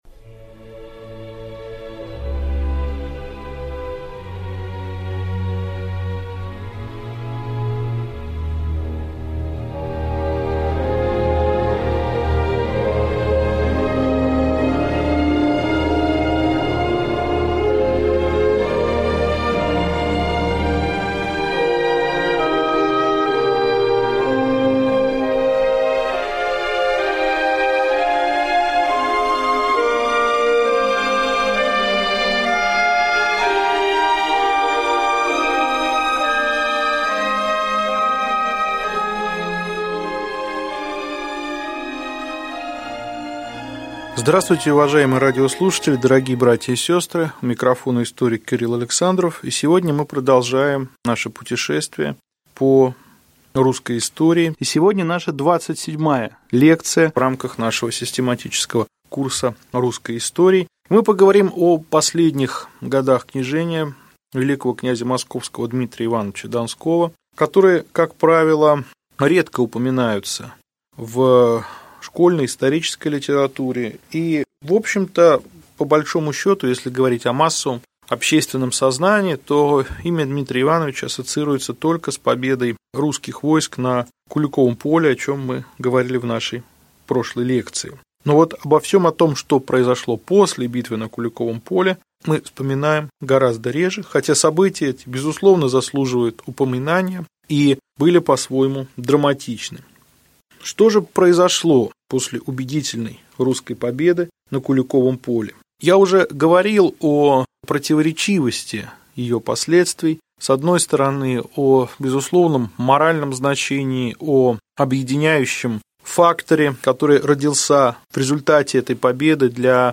Аудиокнига Лекция 27. После Куликовской битвы. Окончание правления Вел. кн. Дмитрия Донского | Библиотека аудиокниг